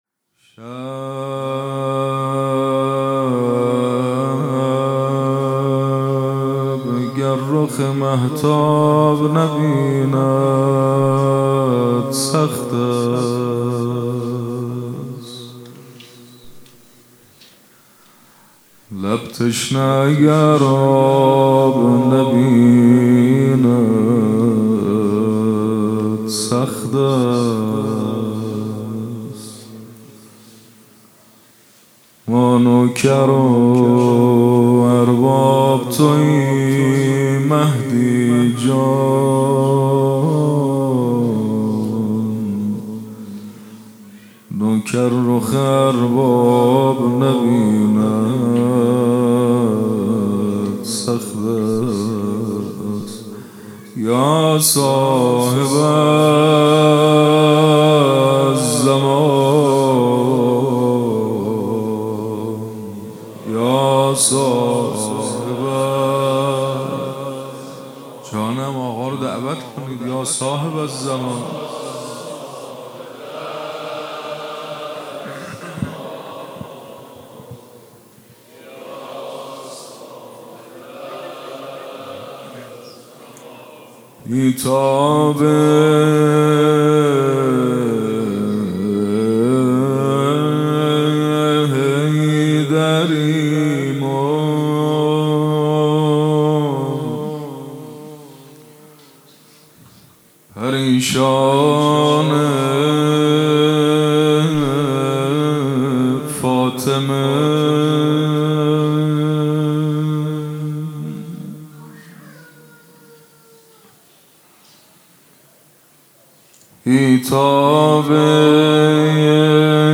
سخنرانی: چگونگی تأثیر سقیفه بر انحراف تاریخ